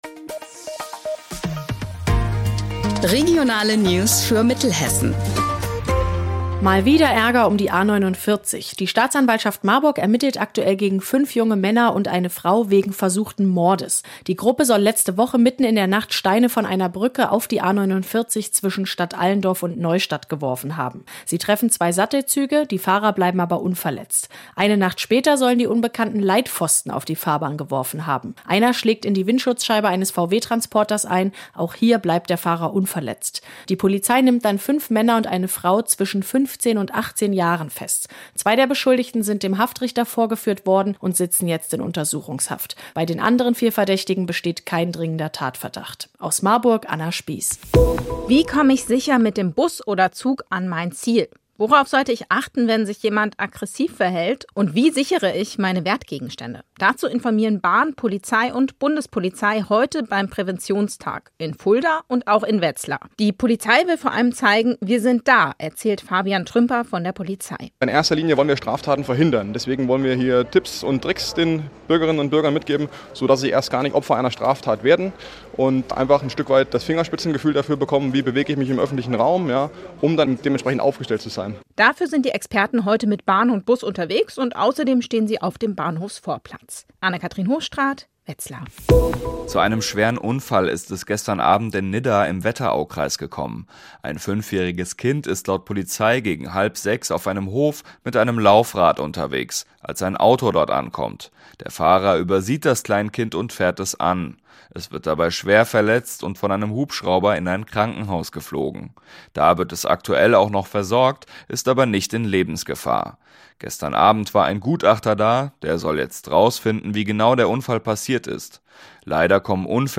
Mittags eine aktuelle Reportage des Studios Gießen für die Region